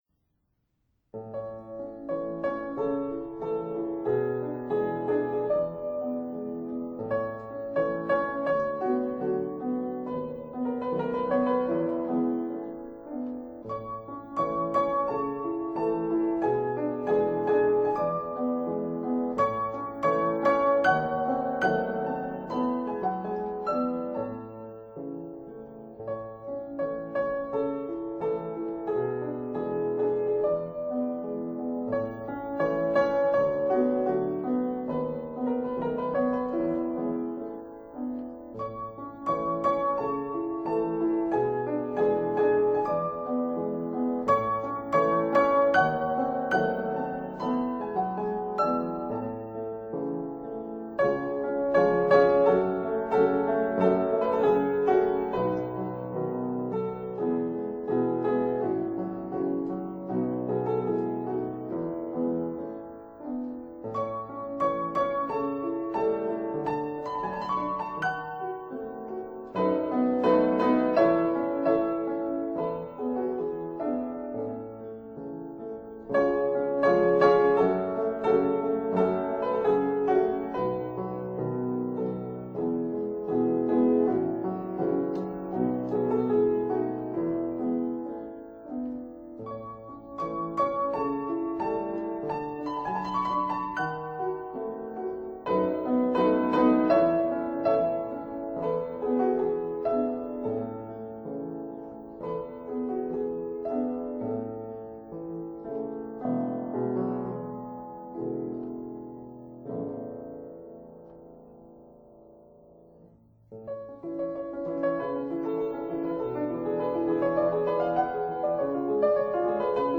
1832 Clementi square piano